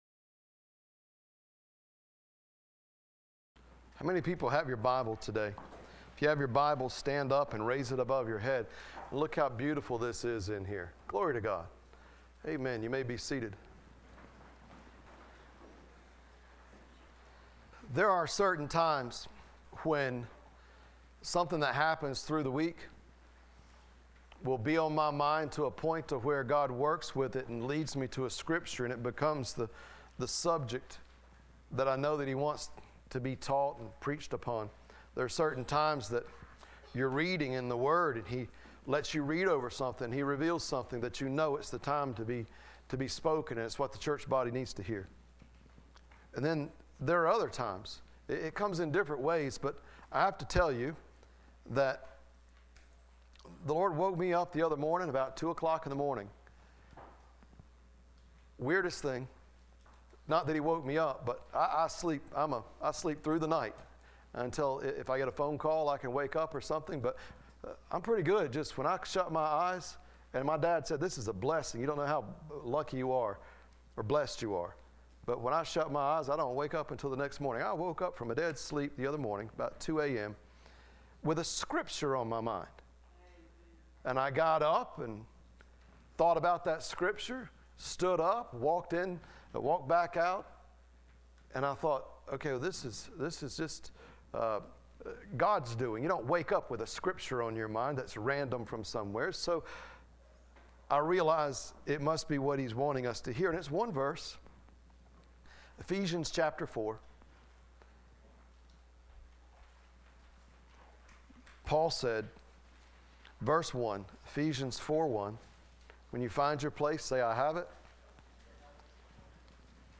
Listen to Sermons - Nazareth Community Church